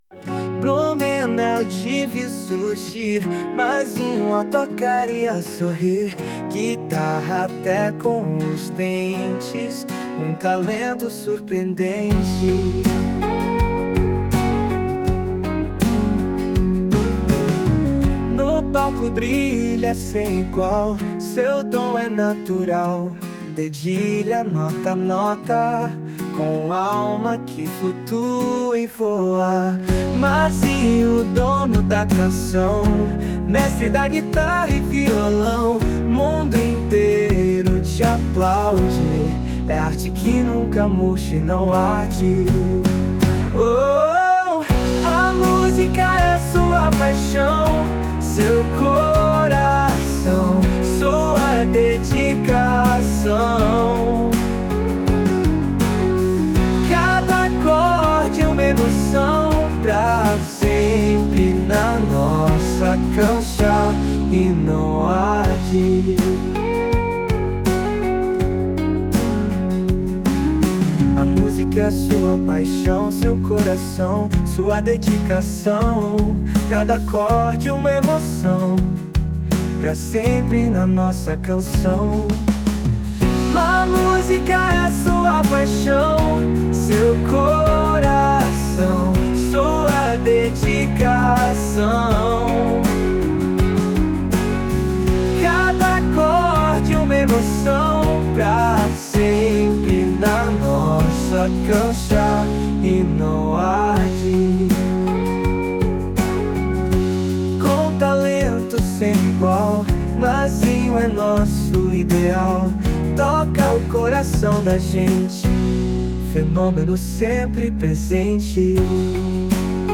3. Composição: A IA gera uma composição original, incluindo letra e melodia.